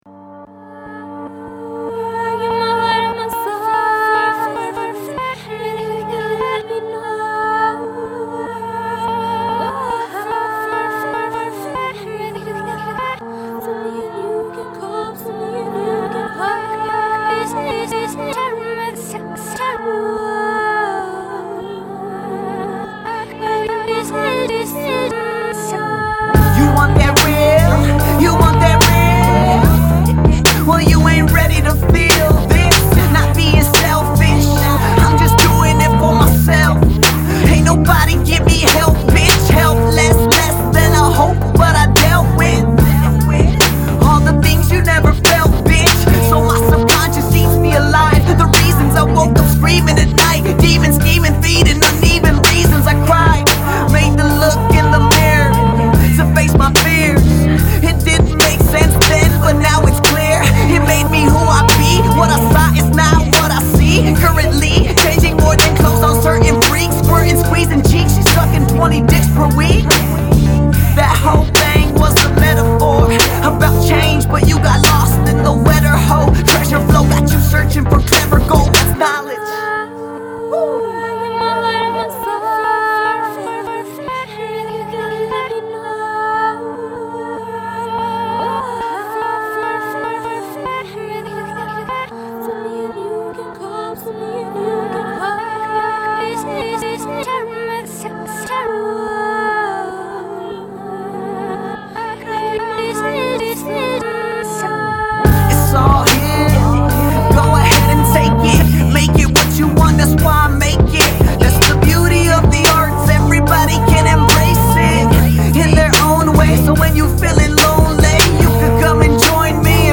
employs a stuttered soul sample